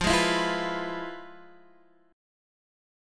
FmM7b5 Chord